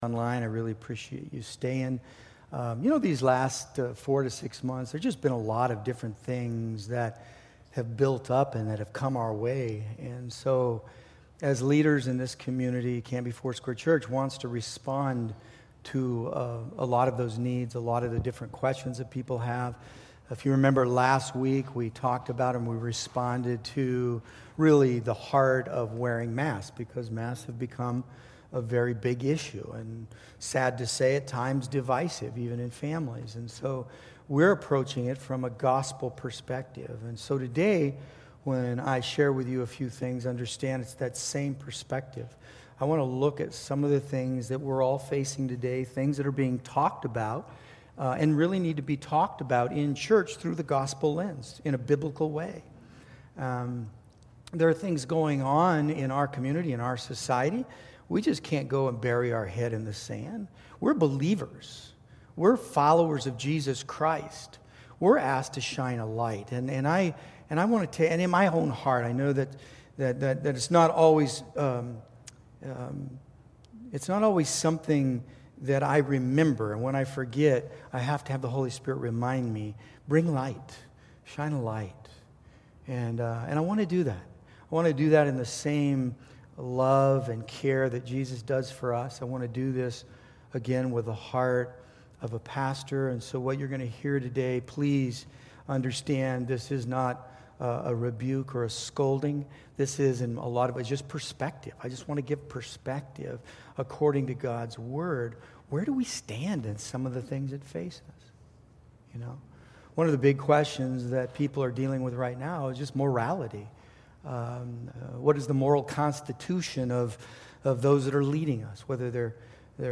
Congregational Address - Week Two